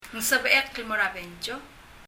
[ŋ sɛbɛ ? ɛk ɛl mɔ ɛrə bensiɔ]